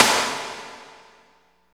53.08 SNR.wav